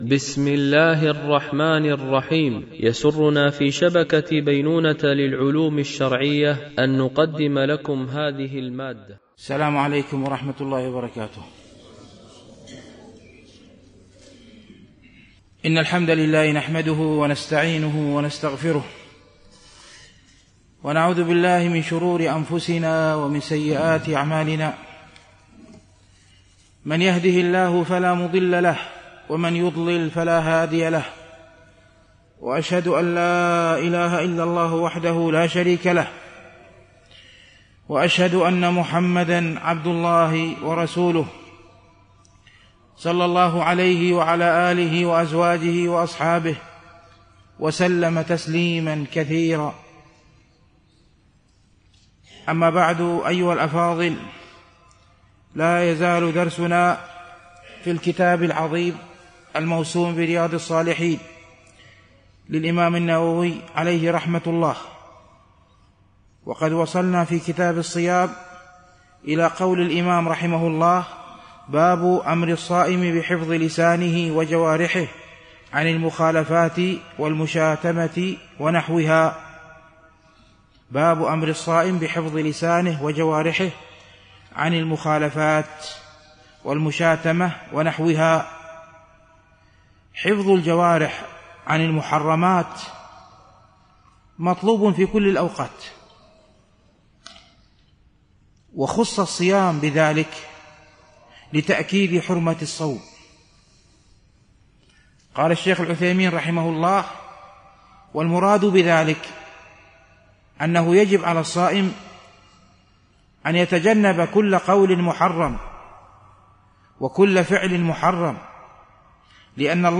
شرح رياض الصالحين – الدرس 329 ( الحديث 1248-1251 )
التنسيق: MP3 Mono 44kHz 96Kbps (VBR)